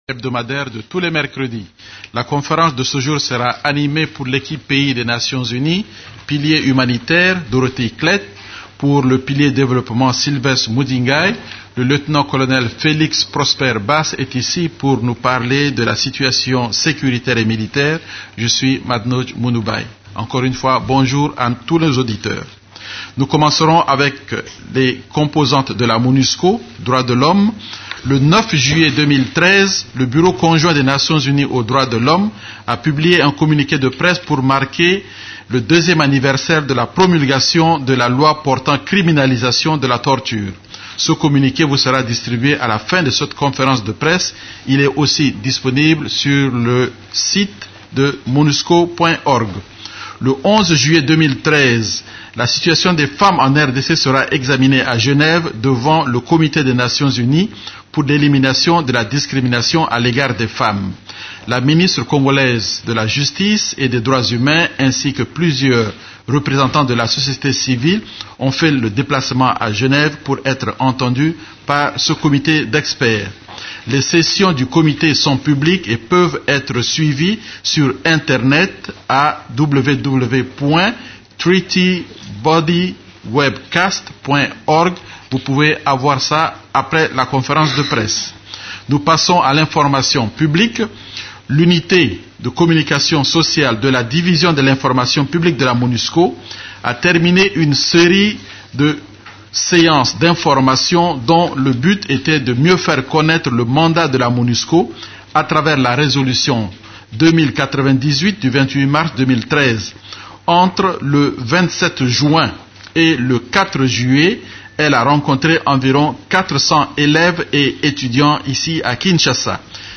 La conférence de presse hebdomadaire des Nations unies en RDC du mercredi 10 juillet a porté sur les sujets suivants: